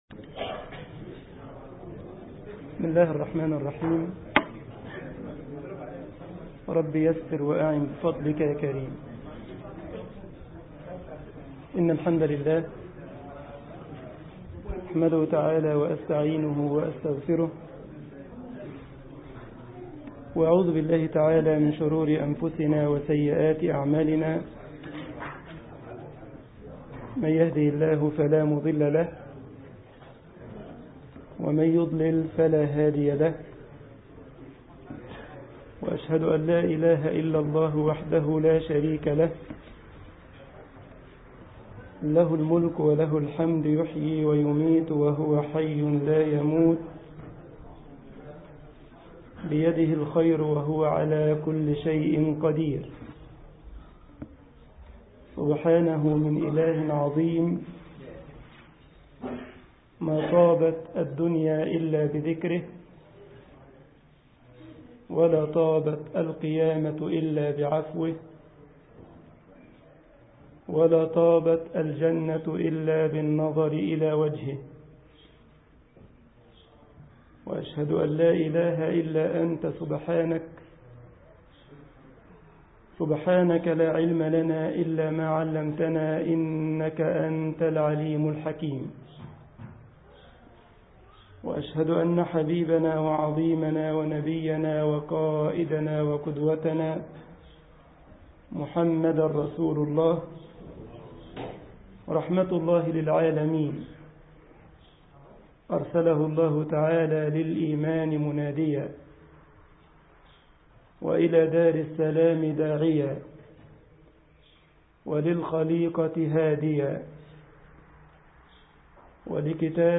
مسجد شتوتغارت ـ ألمانيا محاضرة